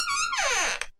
squeaky-door.mp3